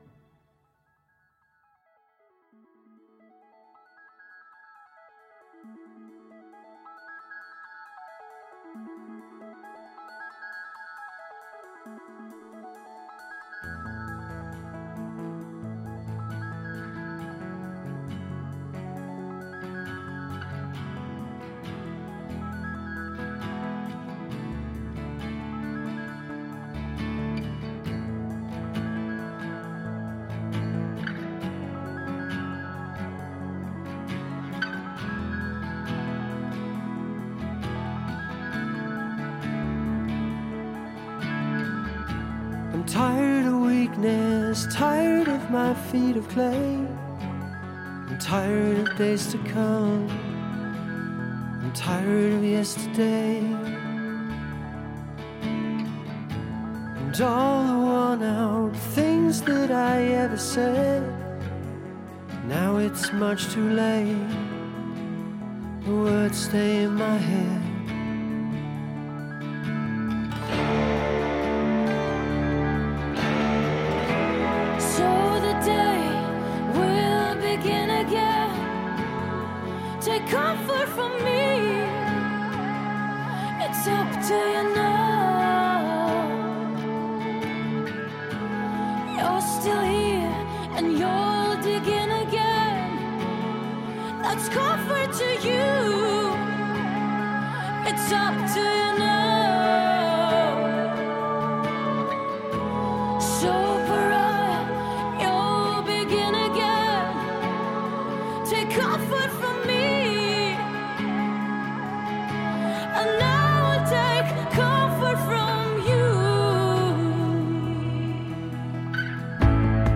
He wanted to go pop-rock.